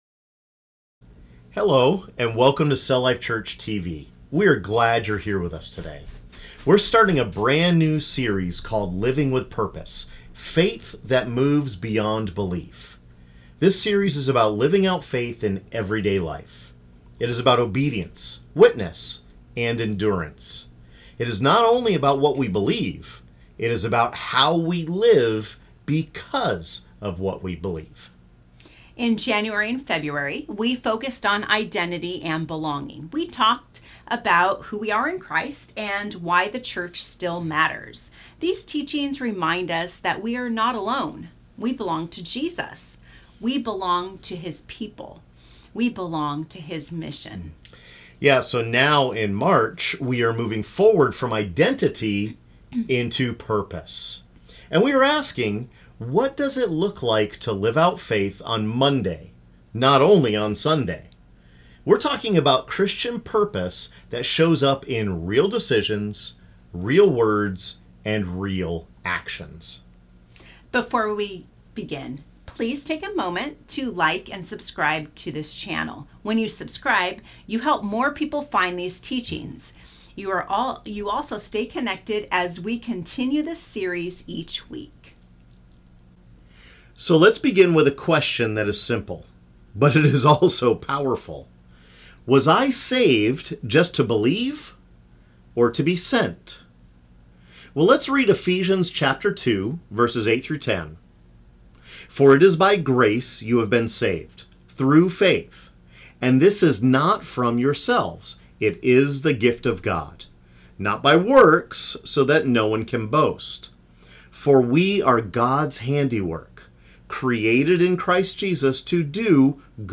Ephesians 2:8–10 reveals Christian purpose by showing that we are saved by grace and created in Christ for good works. This Week 1 teaching helps you live out faith with intention and mission.